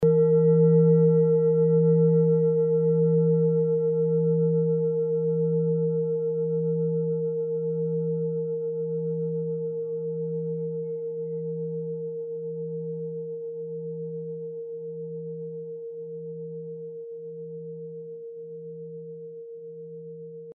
Klangschale Orissa Nr.1
Sie ist neu und wurde gezielt nach altem 7-Metalle-Rezept in Handarbeit gezogen und gehämmert.
(Ermittelt mit dem Filzklöppel)
Die Frequenz des Hopitons liegt bei 164,8 Hz und dessen tieferen und höheren Oktaven. In unserer Tonleiter liegt sie beim "E".
klangschale-orissa-1.mp3